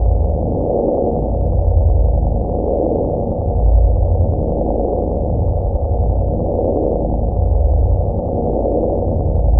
描述：低脏环境回路（定相）
Tag: 循环 香必耳鼻喉科 Warbly 催眠 污垢 相位